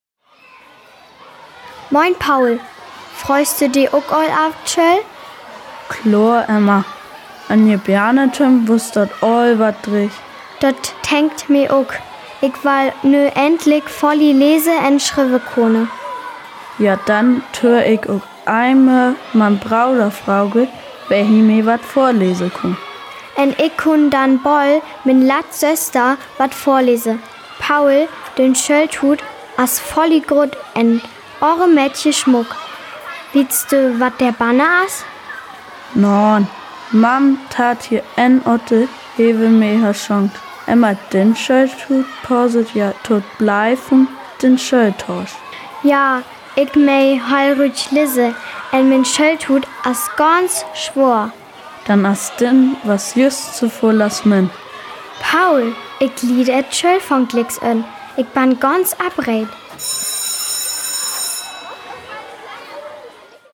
Dialog Paul än Emma - laks 3